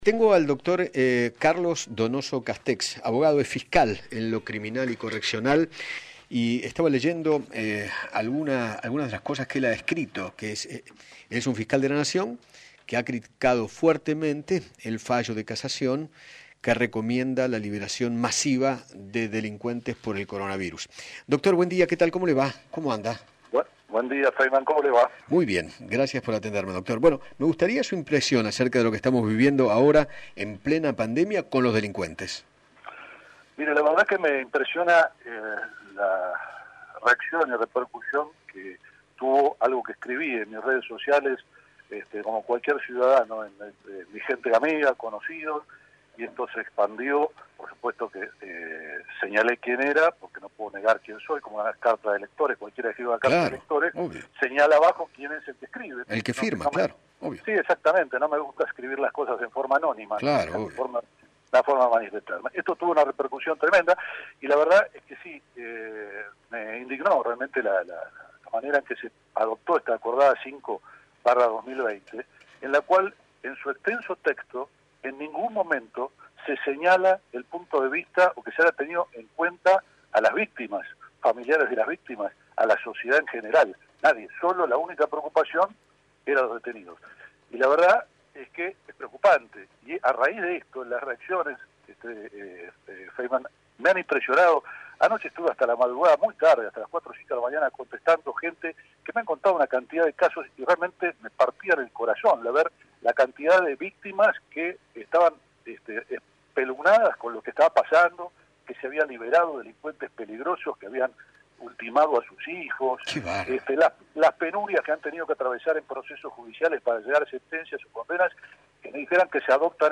Carlos Donoso Castex, Fiscal en lo Criminal y Correccional, dialogó con Eduardo Feinmann sobre el fallo de la Cámara de Casación que recomienda la excarcelación de detenidos considerados de riesgo sanitario.